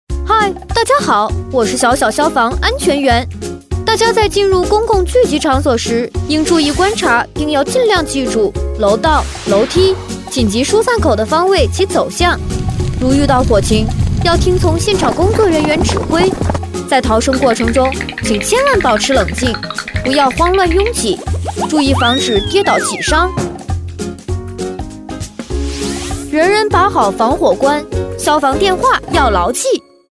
女19-动画【防火逃生指示 -男童8-10岁】
女19-青春甜美（中英） 可爱
女19-动画【防火逃生指示 -男童8-10岁】.mp3